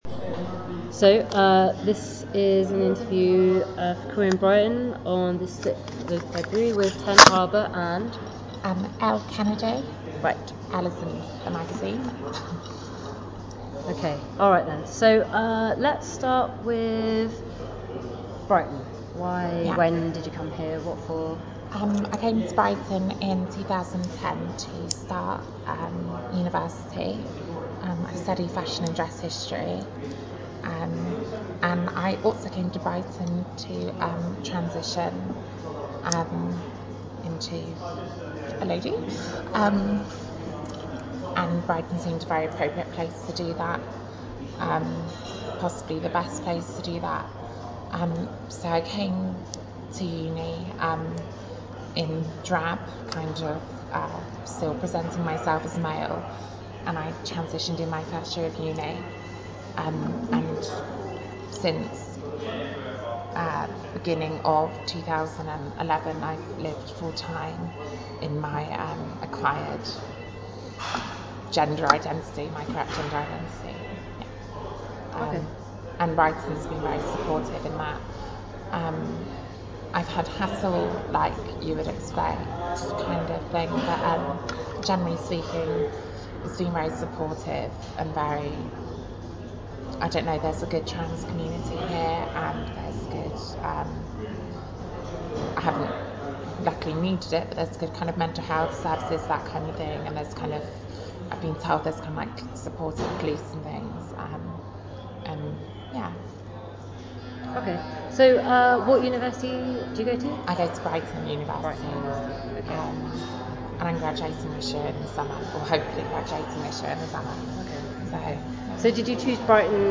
Type Oral History